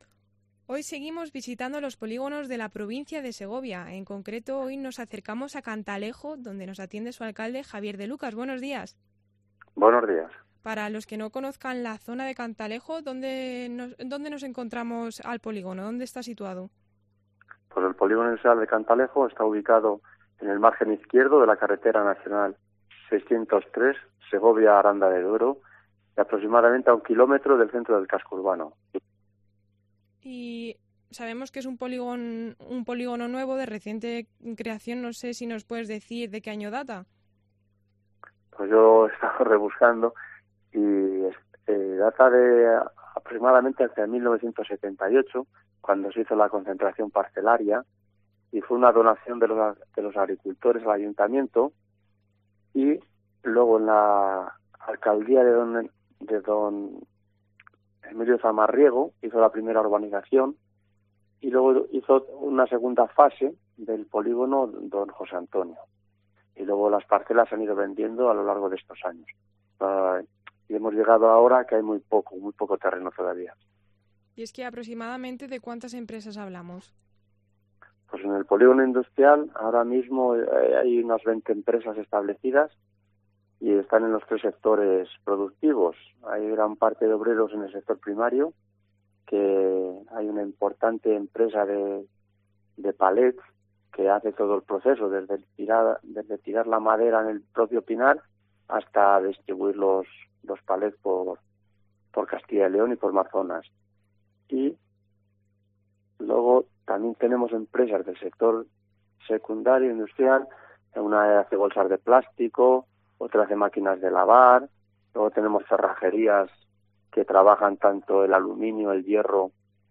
Entrevista al alcalde del municipio, Javier de Lucas